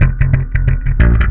SLAPBASS1 -L.wav